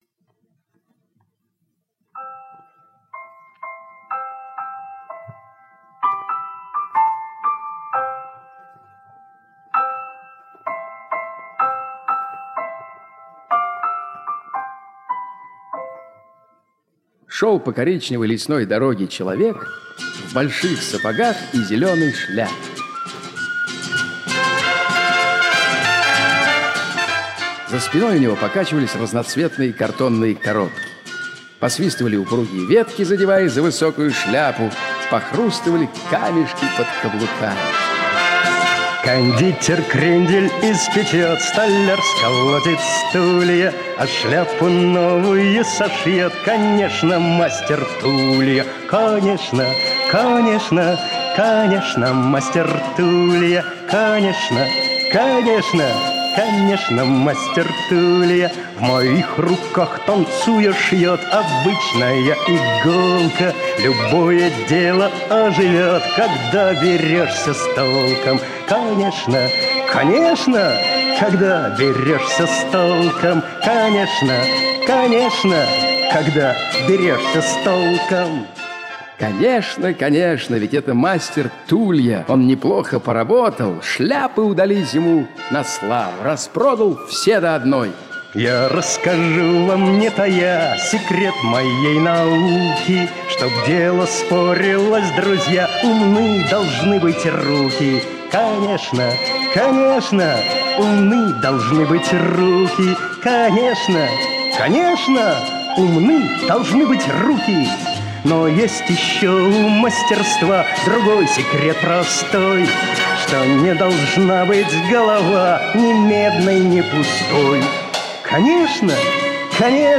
Площадь Картонных Часов - аудиосказка Яхнина - слушать